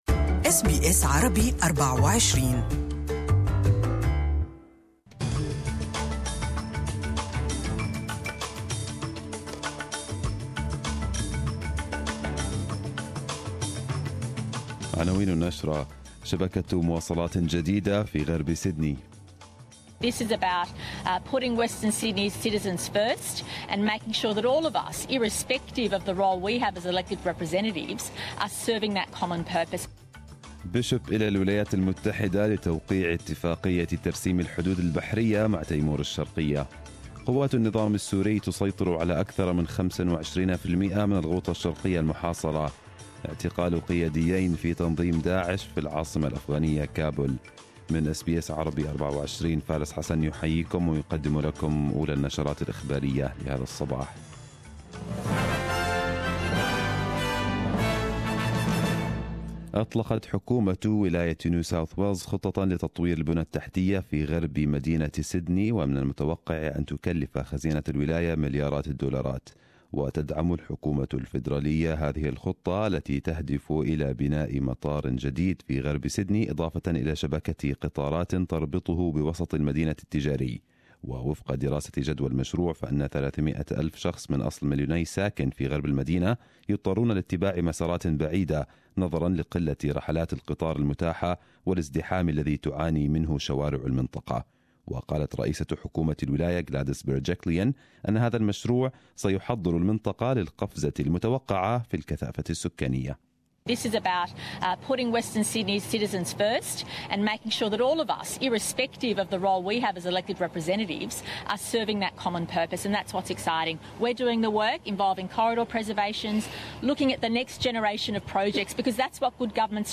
Arabic News Bulletin 05/03/2018